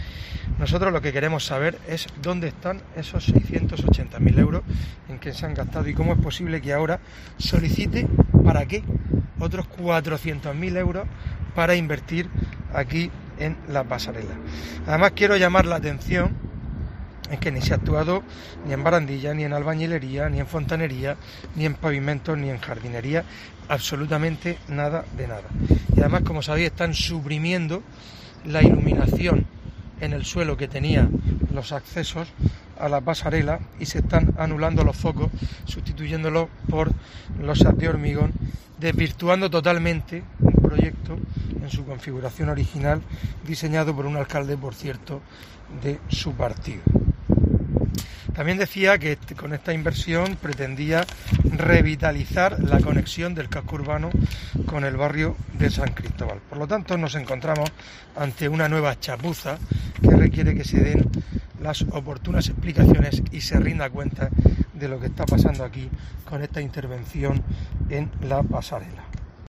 Fulgencio Gil, portavoz del PP sobre Pasarela Manterola